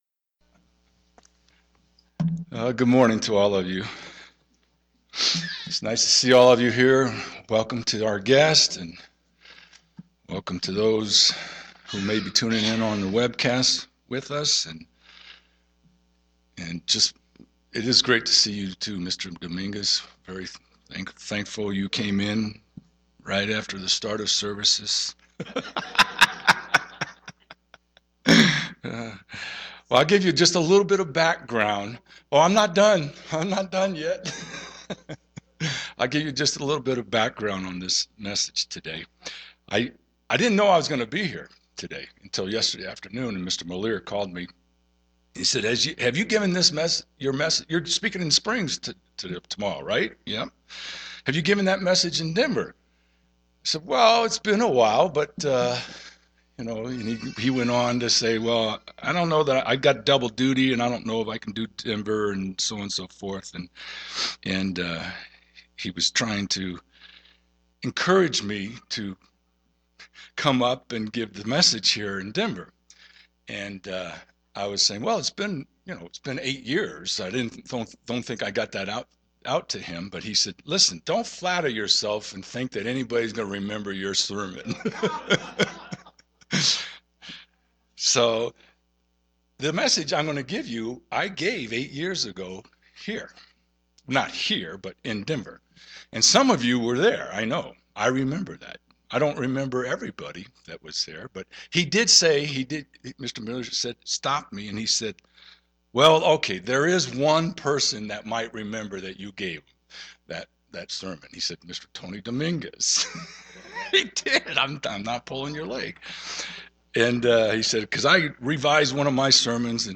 This sermon examines from a biblical perspective why trials and tests are part of the Christian life.